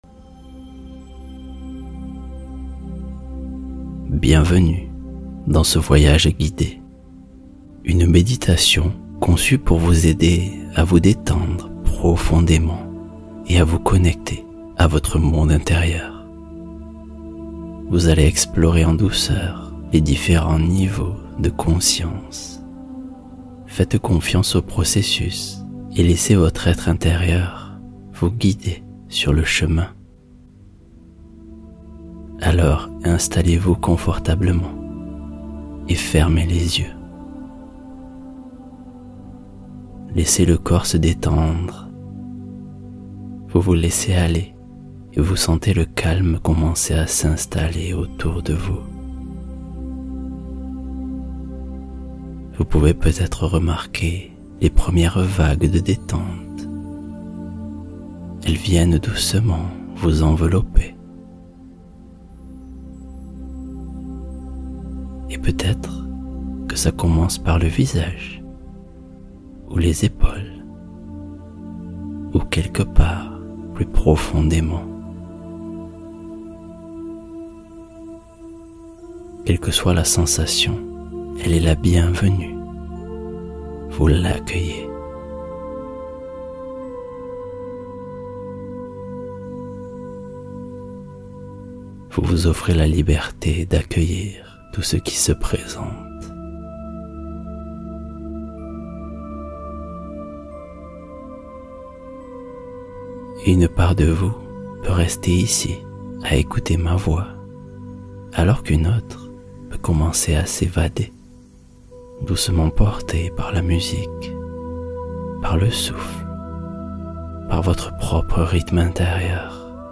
Ce Simple Geste Ce Soir Va TOUT Changer | Méditation Sommeil Réparateur